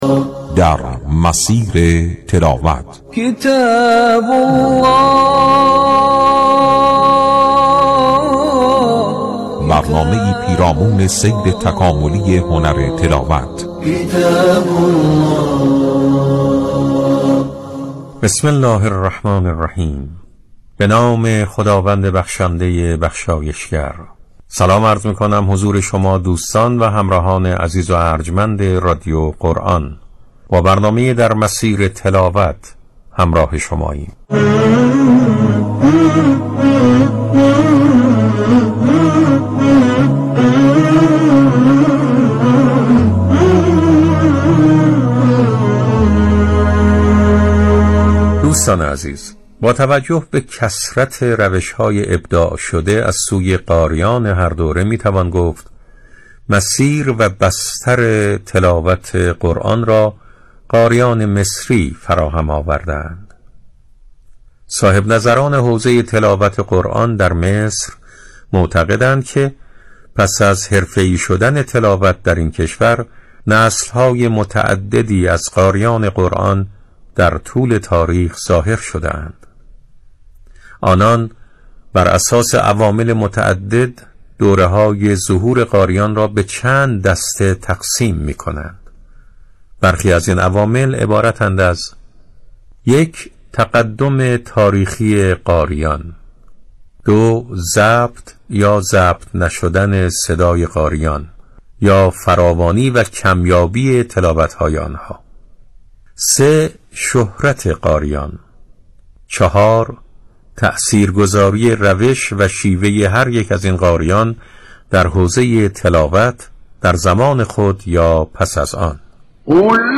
پخش قطعاتی از فرازهایی ناب از قاریان و بیان نکاتی جذاب از روش‌های سبک تلاوت قاریان از جمله بخش‌های این برنامه است.